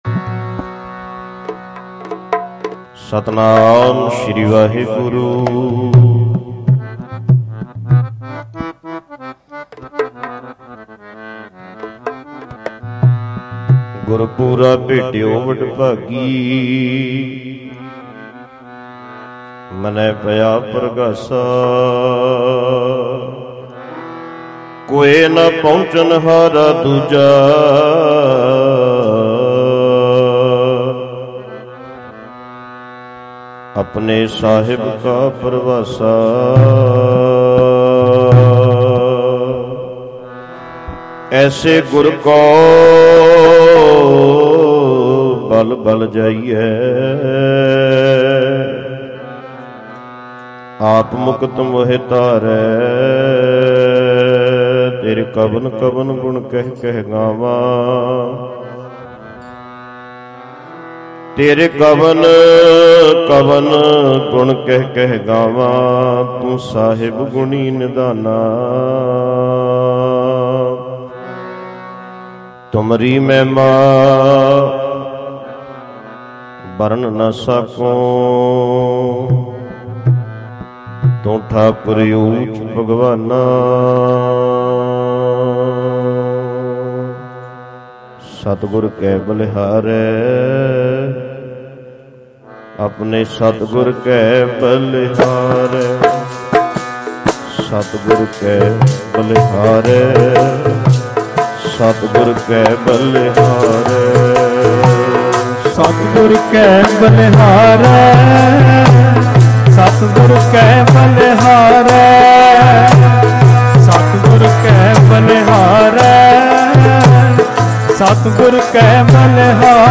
Sehaj Path